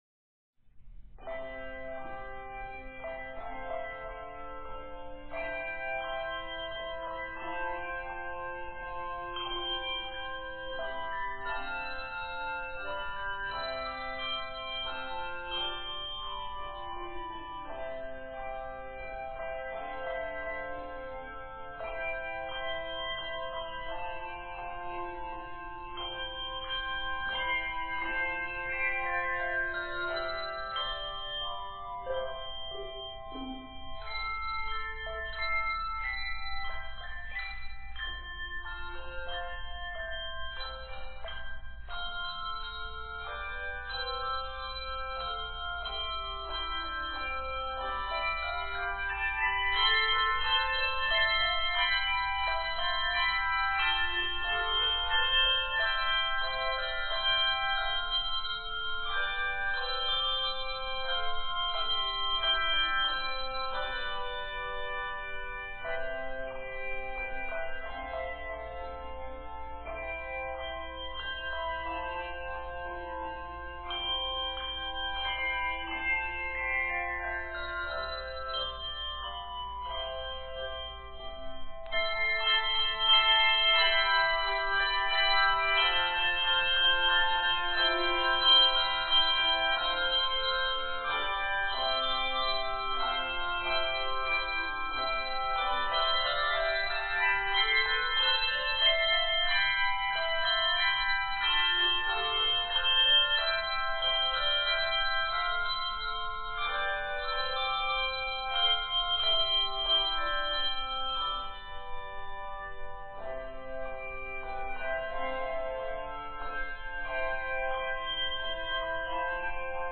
transcription of a minuet for piano
This piece is set in C Major and is 72 measures.